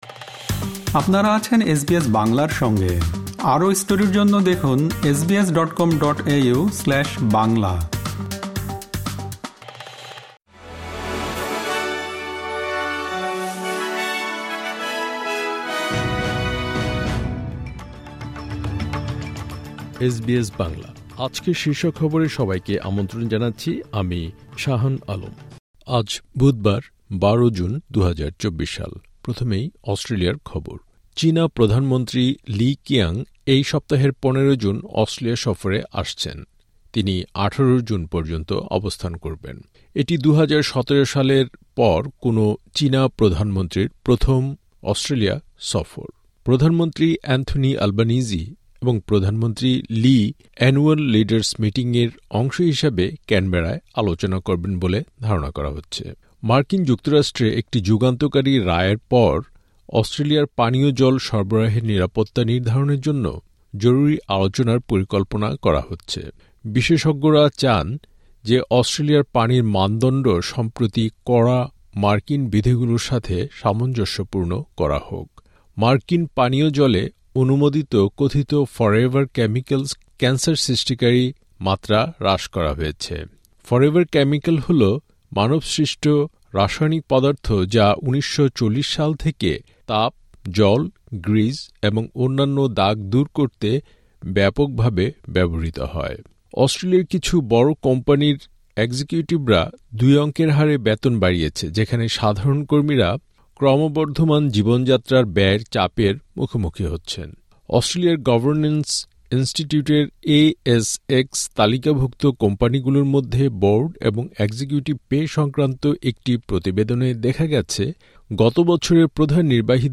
আজকের শীর্ষ খবর চীনা প্রধানমন্ত্রী লি কিয়াং এই সপ্তাহের ১৫-১৮ জুন অস্ট্রেলিয়া সফরে আসছেন, এটি ২০১৭ সালের পর কোনো চীনা প্রধানমন্ত্রীর প্রথম সফর।